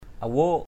/a-wo:ʔ/ (d.) con quốc, chim cuốc = poule d’eau. water hen.